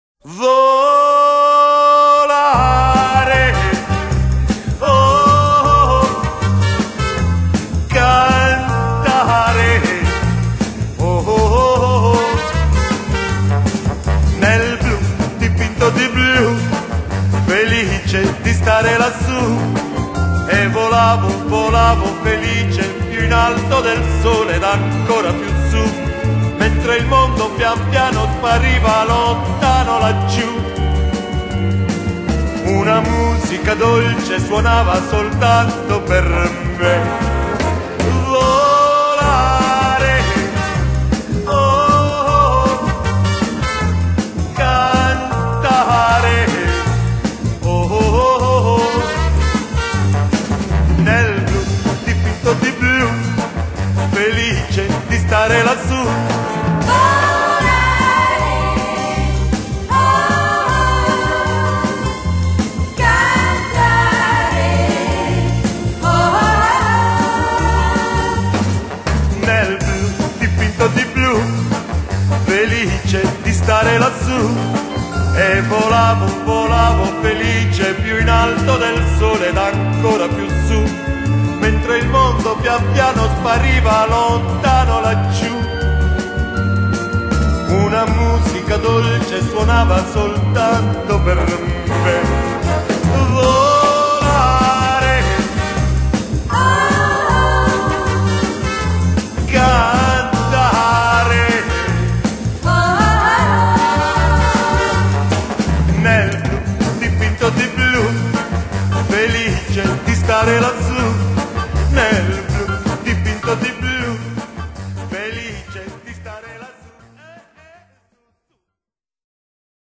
Italian Retro Pop